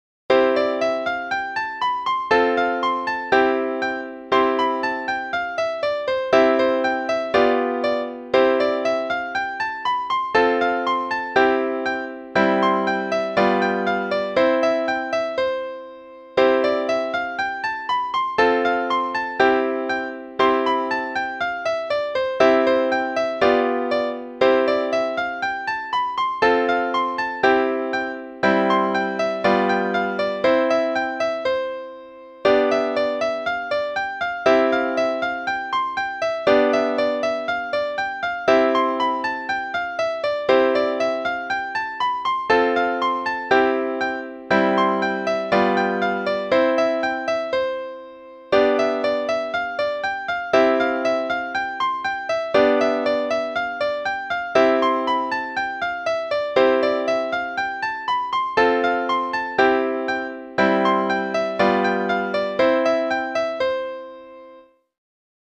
Czerny 15 (qn=120).mp3